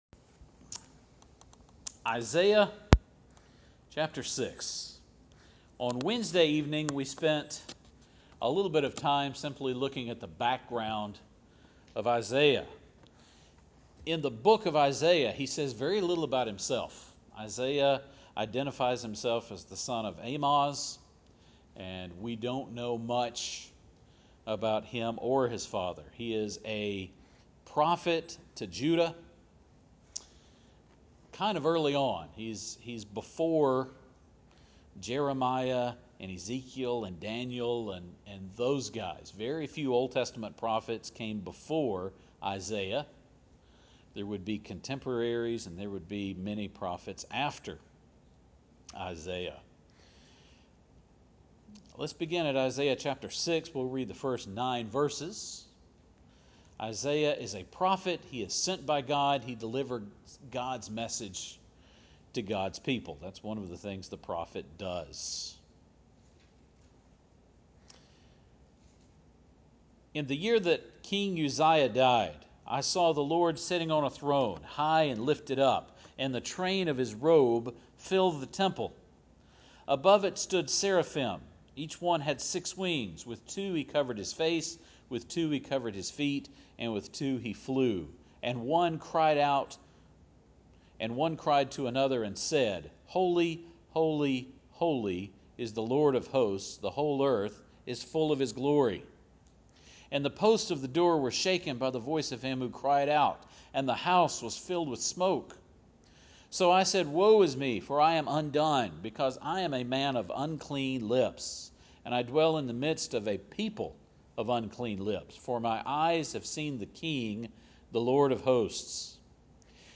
Isaiah served during a time that God was judging Israel. Judgement and salvation are both from God. Much of this sermon compares Isaiah 6 and Revelation 4 illustrating that the Bible has many writers but there is one author.